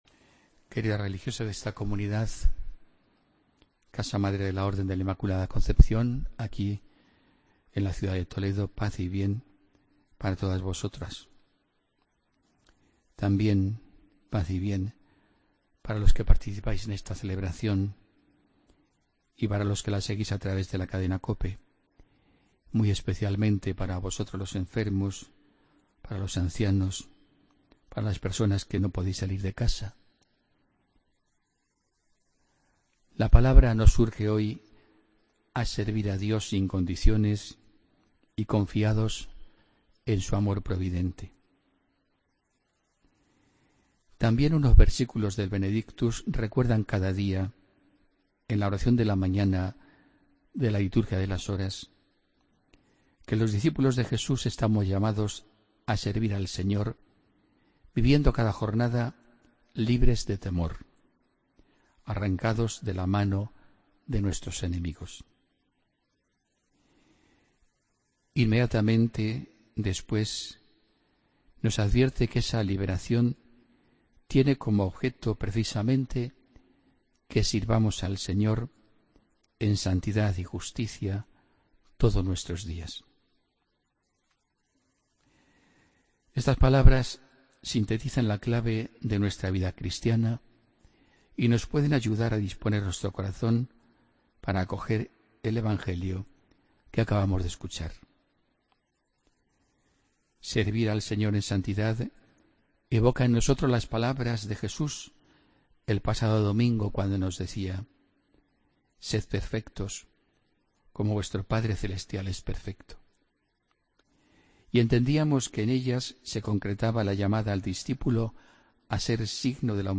Homilía del domingo 26 de febrero de 2017